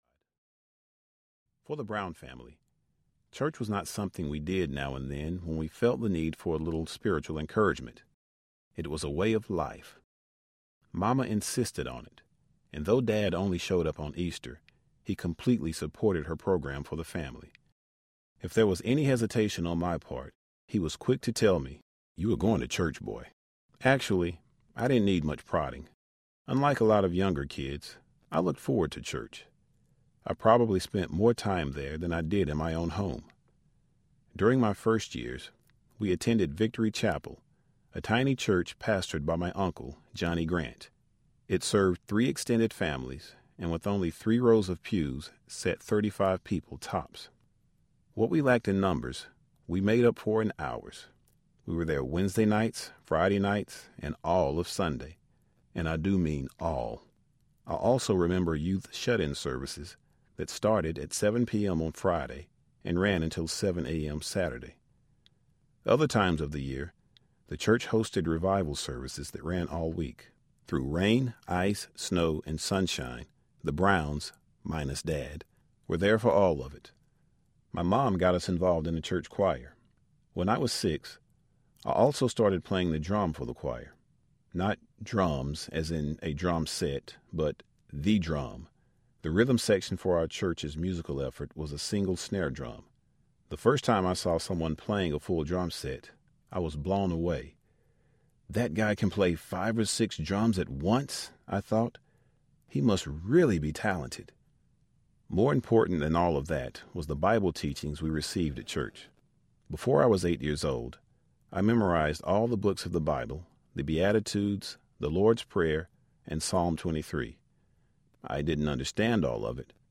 The Making of a Man Audiobook
6.4 Hrs. – Unabridged